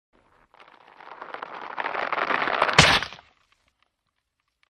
14. Звук разрыва сильно натянутого каната или троса
kanat-2.mp3